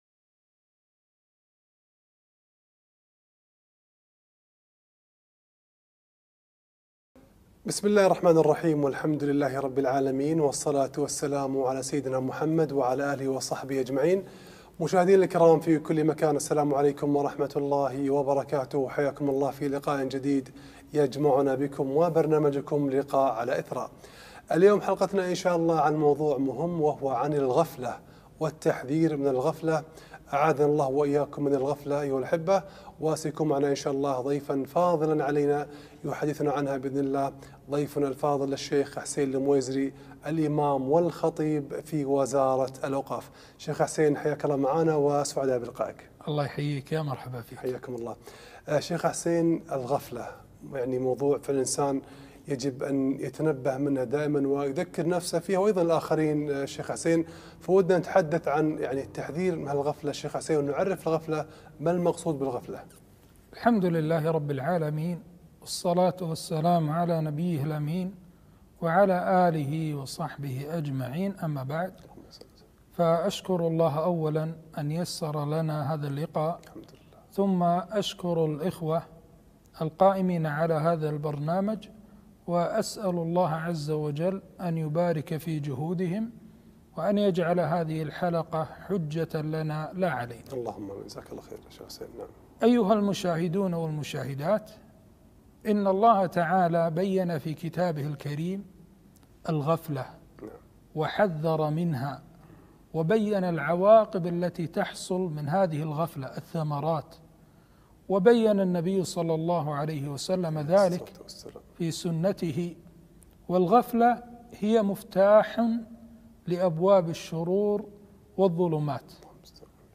التحذير من الغفلة - لقاء تلفزيوني في قناة إثراء